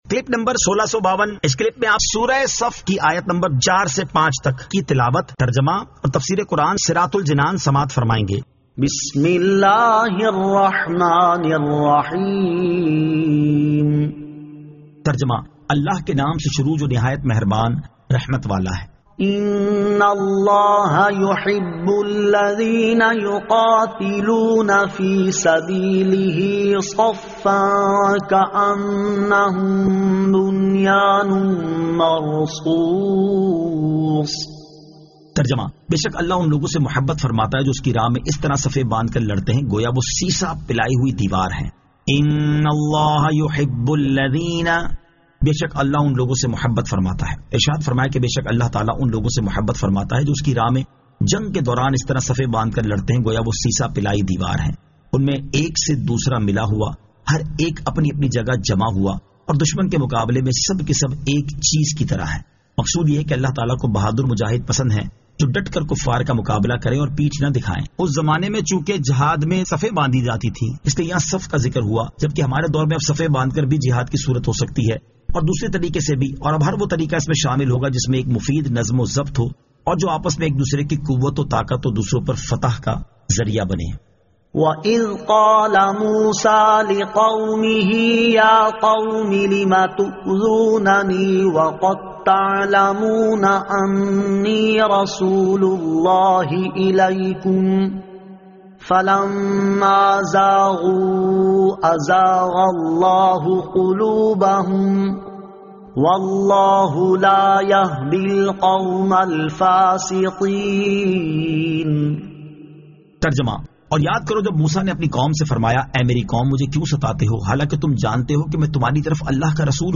Surah As-Saf 04 To 05 Tilawat , Tarjama , Tafseer